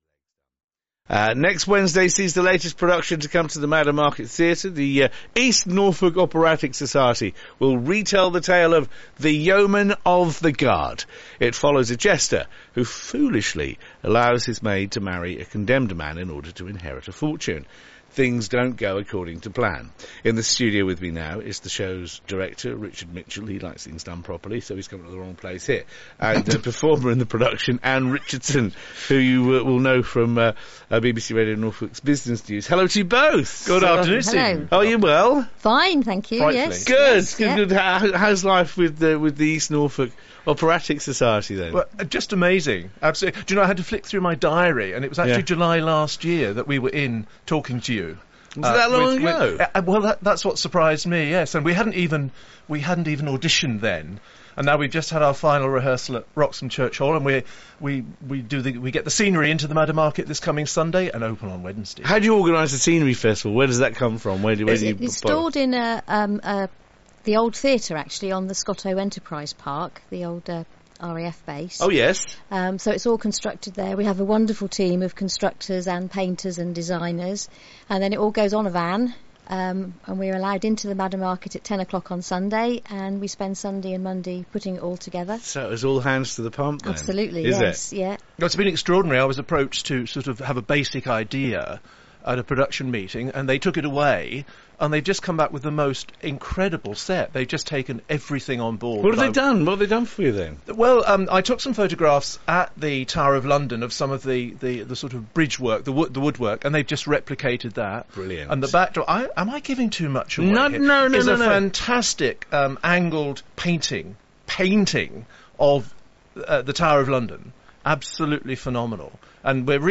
Publicity Interview broadcast on BBC Radio Norfolk on 28 April 2017